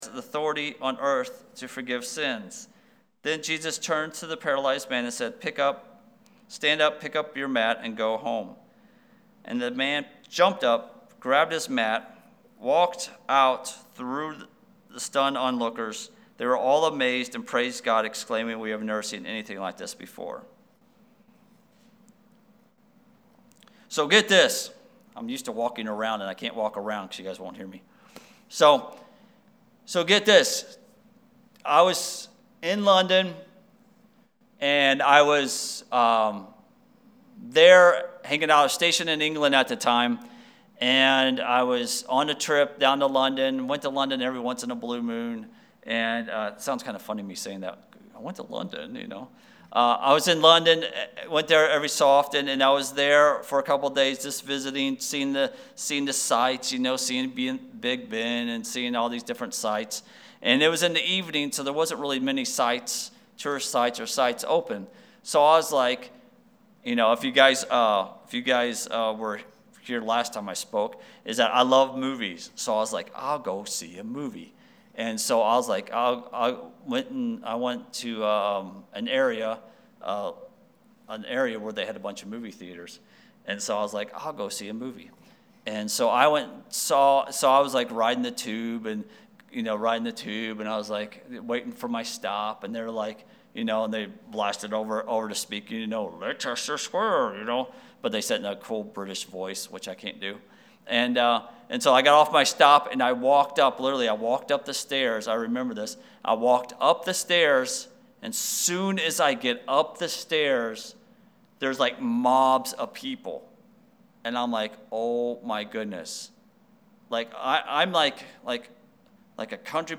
Sermons | Friendship Assembly of God